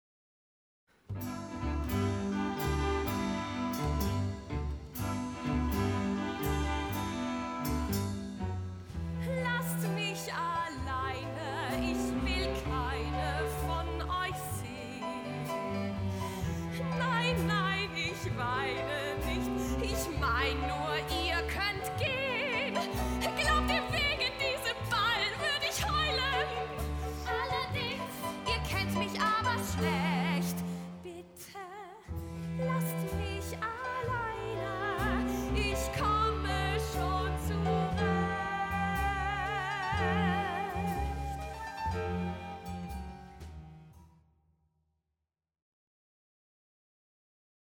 Münchner Erstaufführung am 17. Januar 2015 in der Reithalle
Musiker des Orchesters des Staatstheaters am Gärtnerplatz